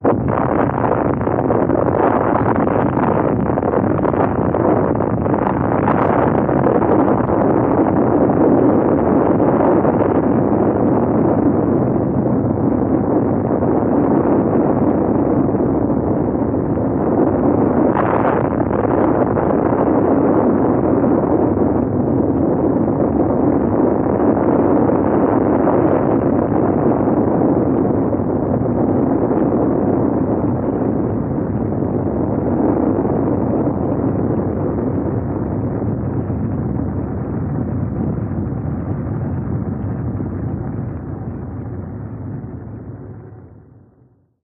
На этой странице представлены записи звуков атомного взрыва — мощные, устрашающие и заставляющие задуматься о хрупкости мира.
Звук взрыва настоящей атомной бомбы ретро запись